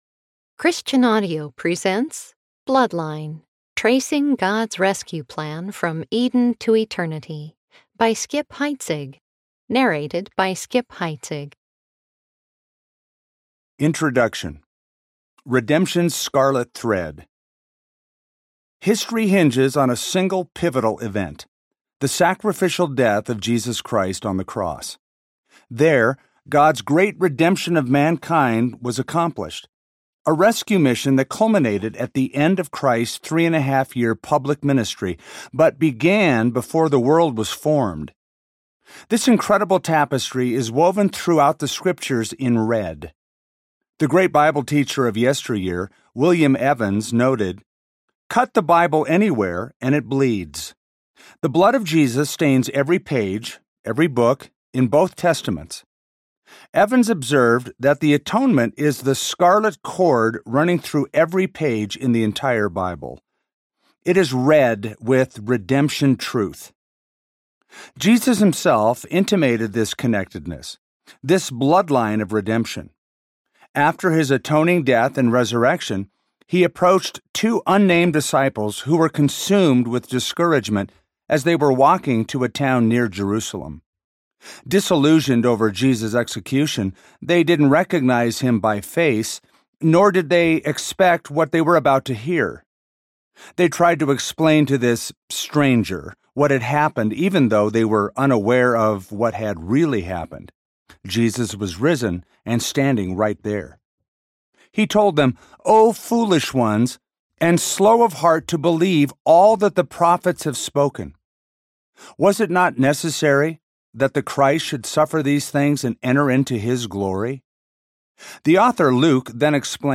Bloodline Audiobook
9.15 – Unabridged